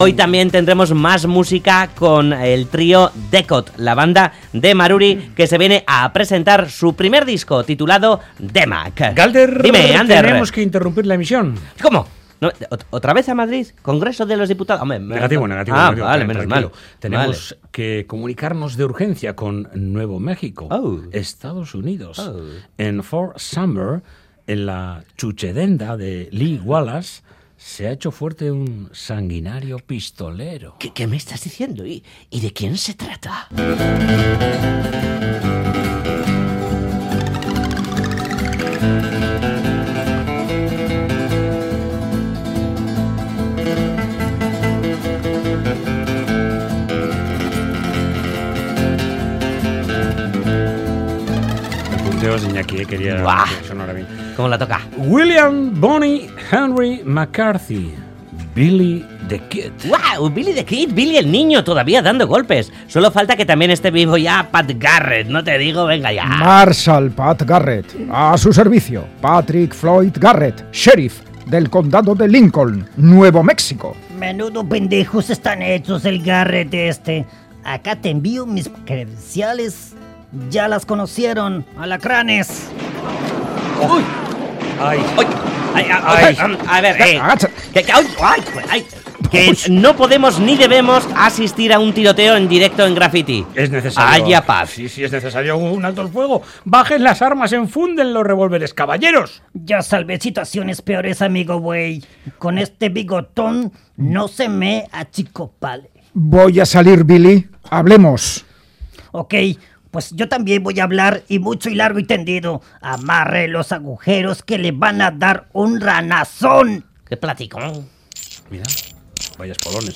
Billy the Kid, se entrega a Pat Garret, en directo, para ser becario